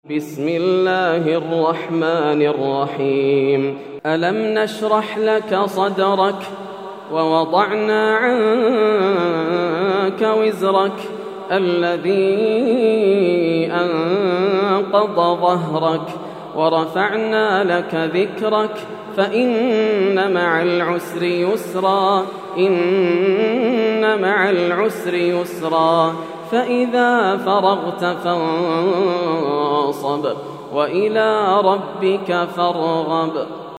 سورة الشرح > السور المكتملة > رمضان 1431هـ > التراويح - تلاوات ياسر الدوسري